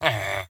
villager